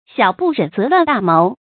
小不忍则乱大谋 xiǎo bù rěn zé luàn dà móu
小不忍则乱大谋发音